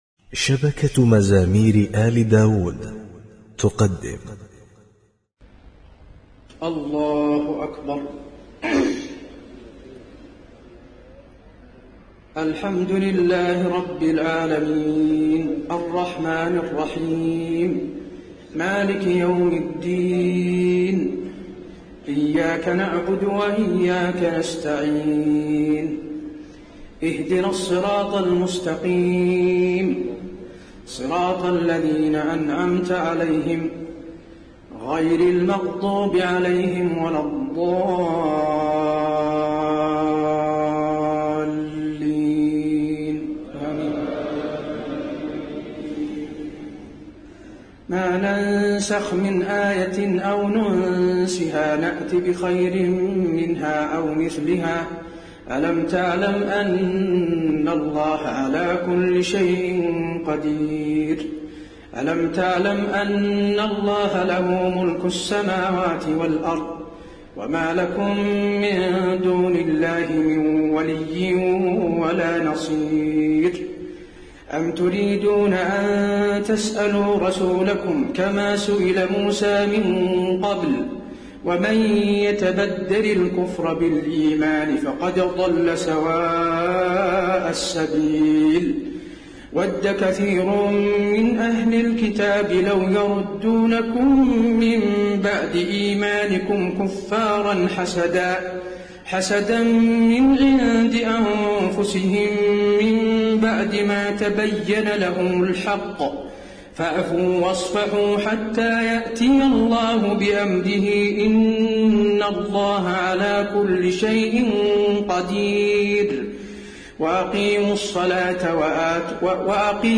تهجد ليلة 21 رمضان 1432هـ من سورة البقرة (106-176) Tahajjud 21 st night Ramadan 1432H from Surah Al-Baqara > تراويح الحرم النبوي عام 1432 🕌 > التراويح - تلاوات الحرمين